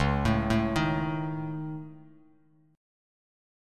Dbsus2#5 chord